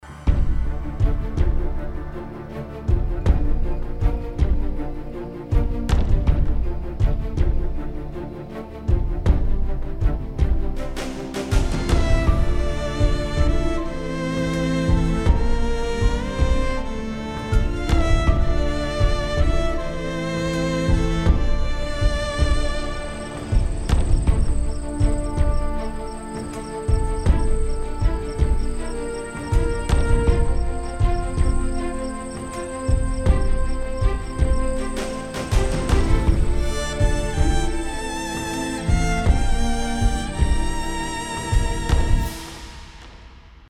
malayalam movie bgm-1